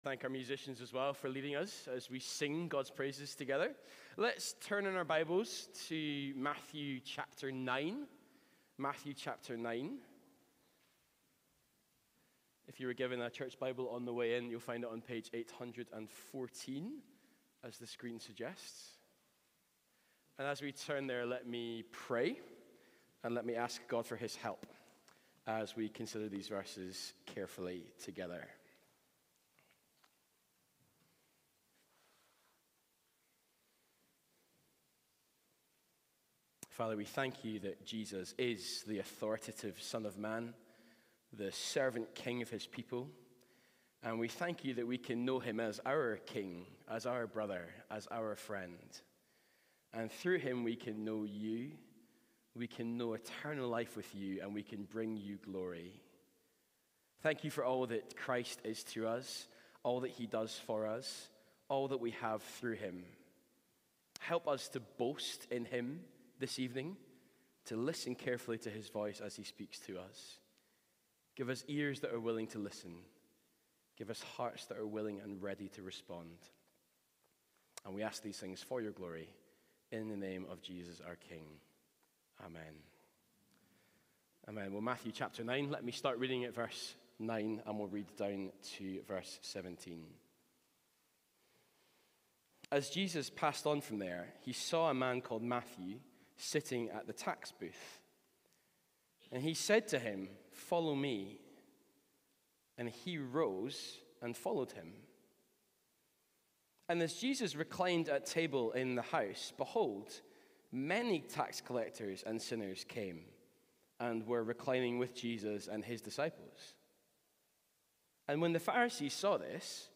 Sermon Notes